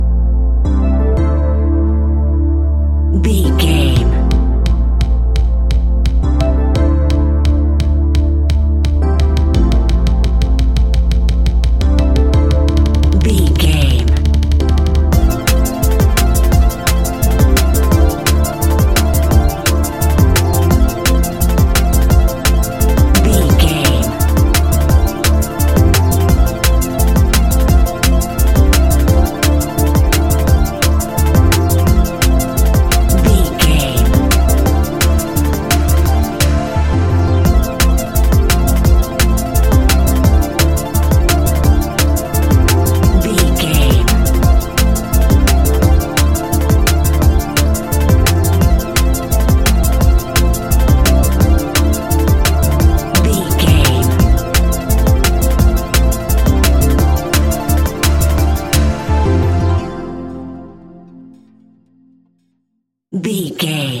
Ionian/Major
A♯
electronic
techno
trance
synths